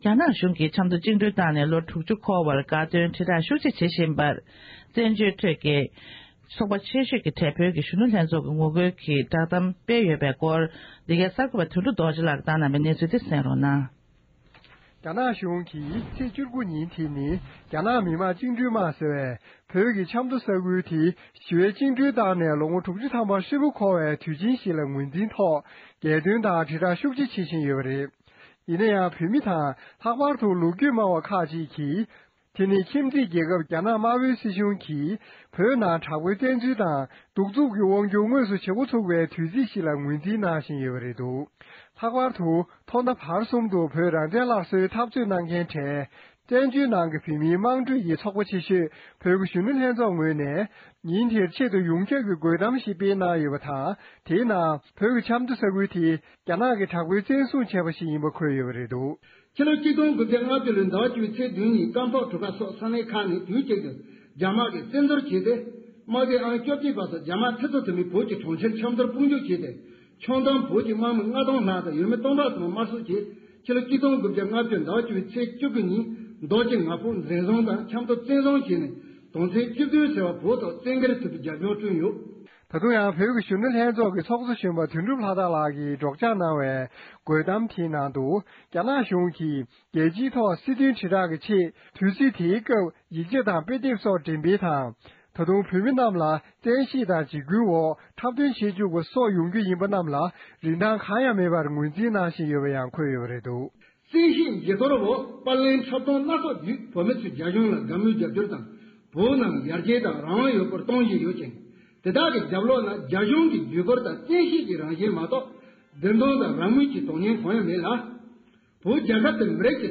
རྒོལ་གཏམ་སྤེལ་བའི་བགྲོ་གླེང༌།